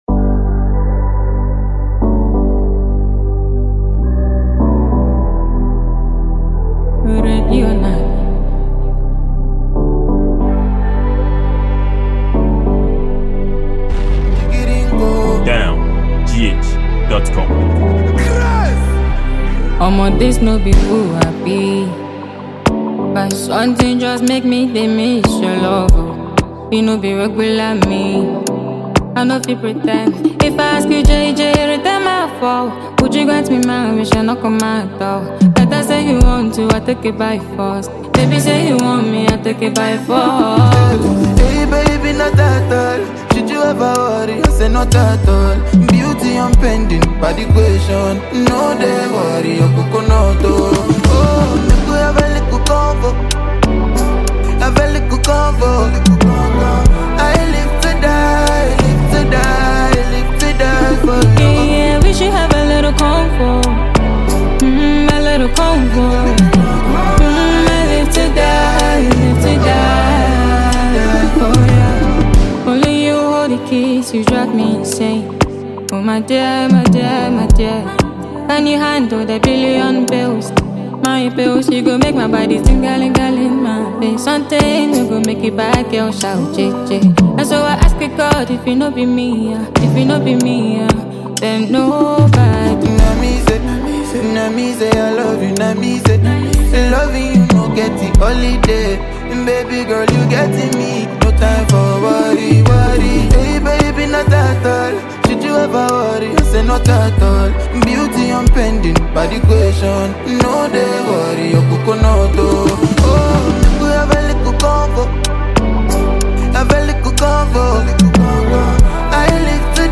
a talented vocalist with a soulful voice
soulful vocals